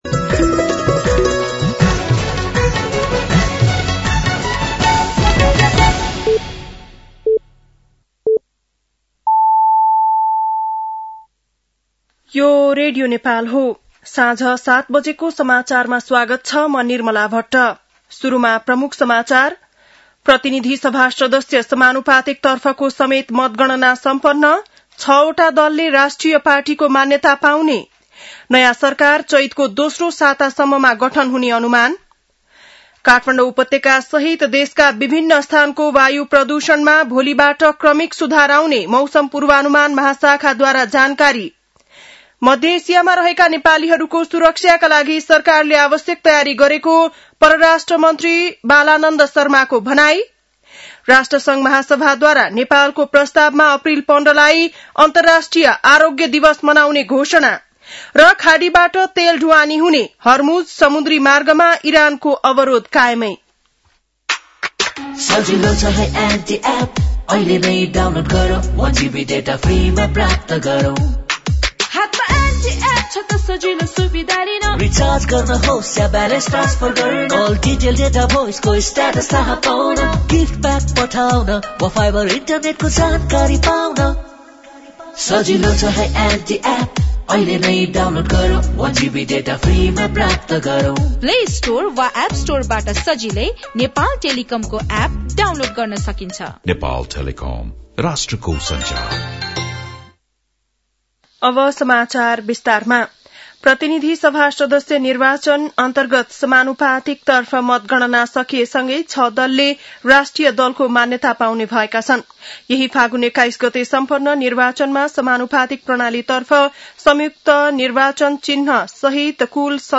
बेलुकी ७ बजेको नेपाली समाचार : २७ फागुन , २०८२
7-pm-nepali-news-11-27.mp3